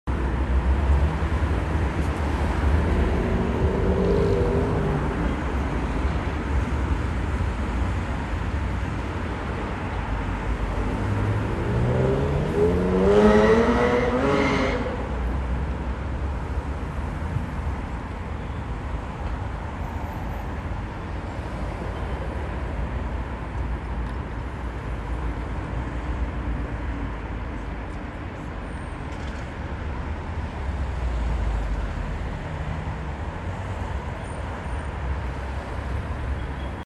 Download City sound effect for free.